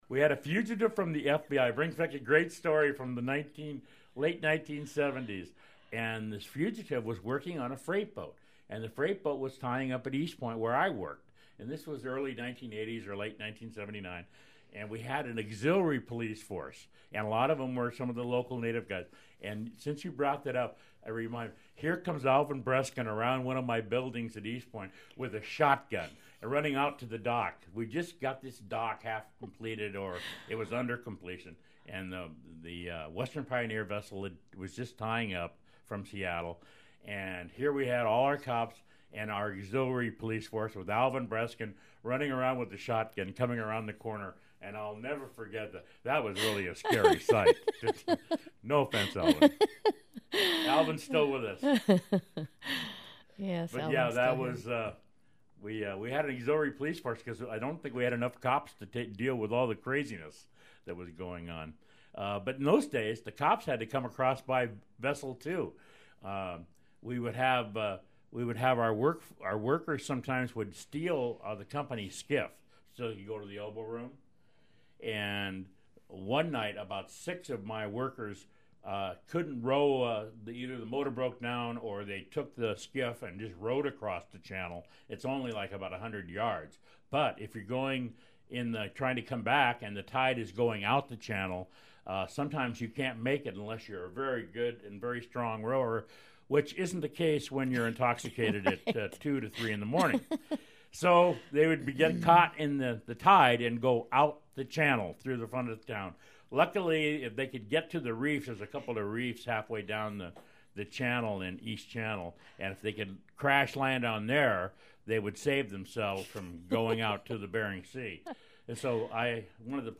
Unalaska, AK – The StoryCorps project records conversations between ordinary people about their lives.